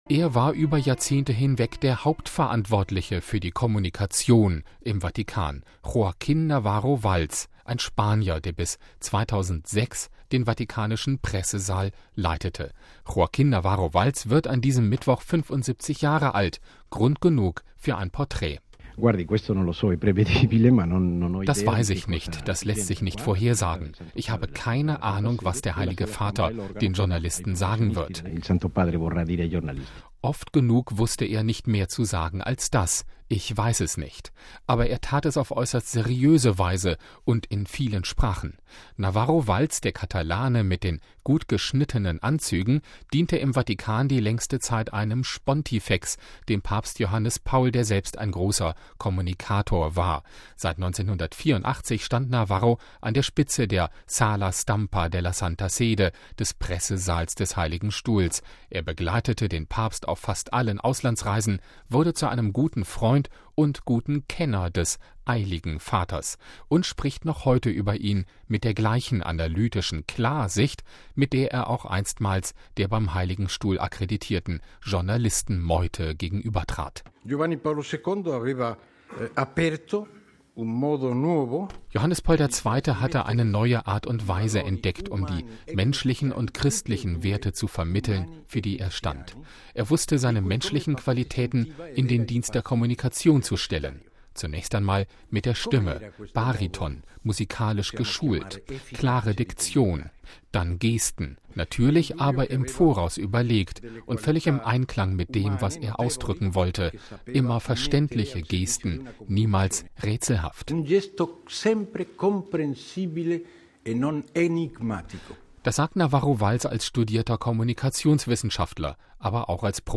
Grund genug für ein Porträt.